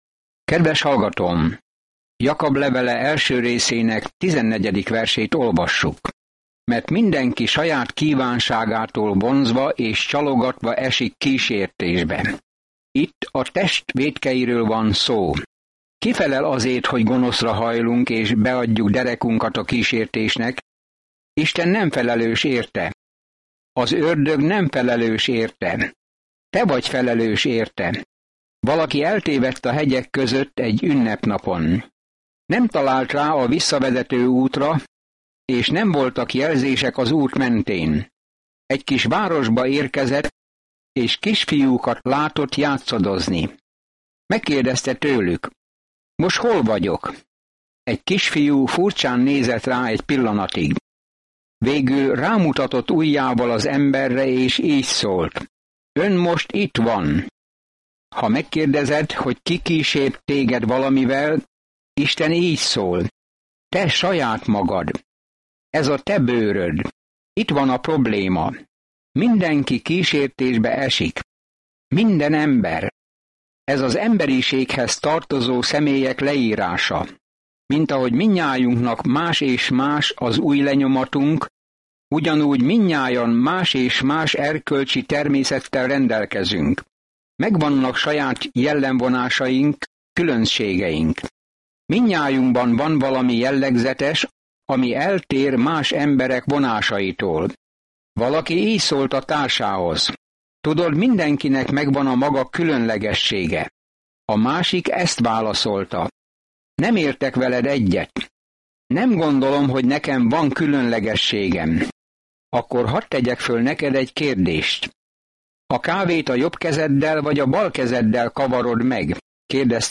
Szentírás Jakab 1:14-17 Nap 3 Olvasóterv elkezdése Nap 5 A tervről Ha hívő vagy Jézus Krisztusban, akkor tetteidnek tükrözniük kell az új életedet; tegye hitét tettekre. Napi utazás Jakabon keresztül, miközben hallgatod a hangtanulmányt, és olvasol válogatott verseket Isten szavából.